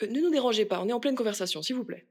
VO_ALL_EVENT_Trop proche de la cible_03.ogg